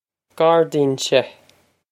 Gawr-jeen-seh
This is an approximate phonetic pronunciation of the phrase.